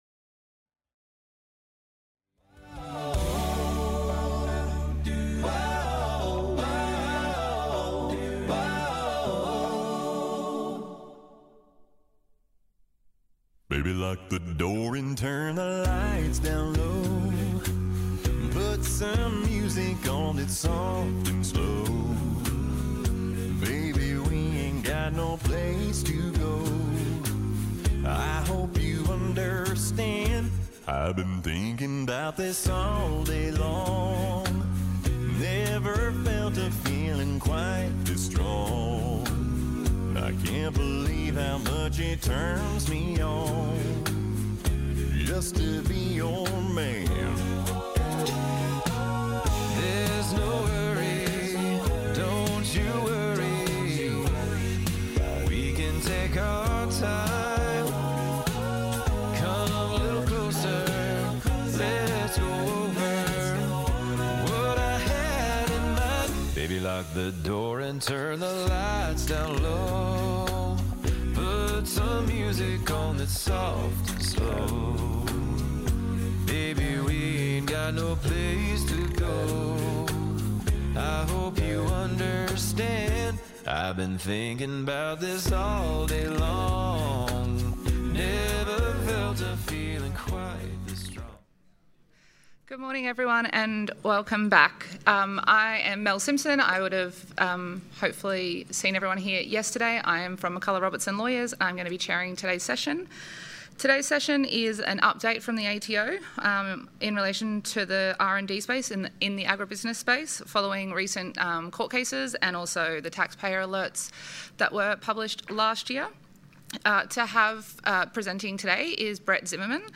Took place at: Stamford Plaza Brisbane